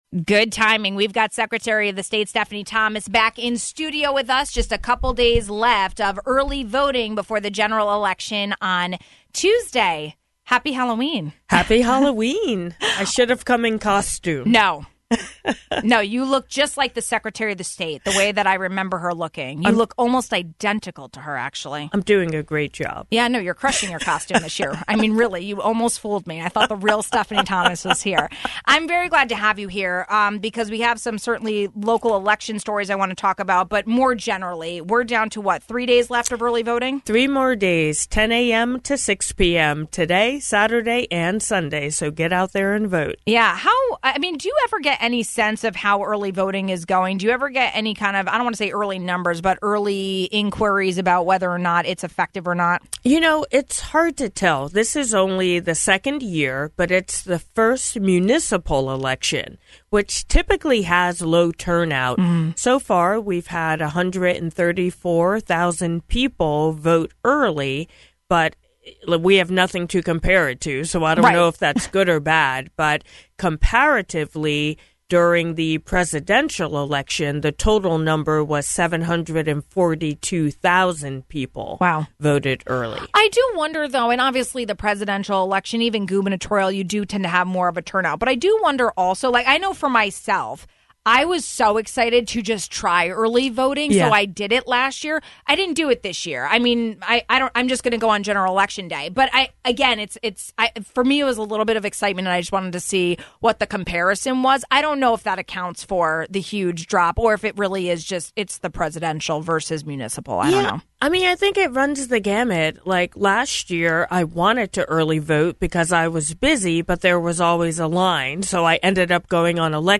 The general election is next Tuesday. We checked in with Secretary of the State Stephanie Thomas about some of the drama in certain municipalities during early voting periods and the latest news for Election Day.